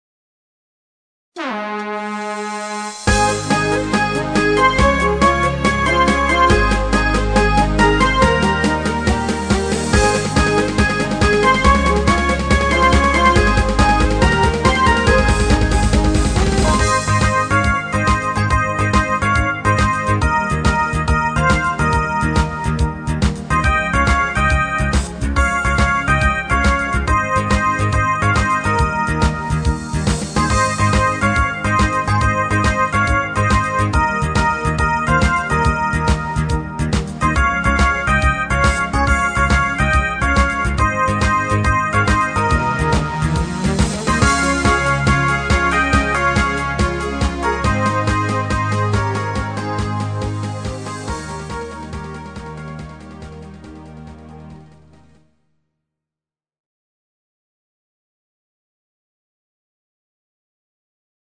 Genre(s): Deutschpop  Partyhits  |  Rhythmus-Style: Discofox